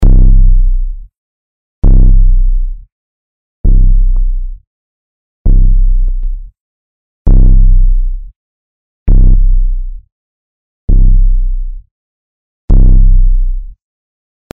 Engine 2 soll mit 7 Steps in 1/8 Länge laufen.
Dieser fügt ein paar kleine Artefakte, ein dezentes Rumpeln oder Klicken hinzu.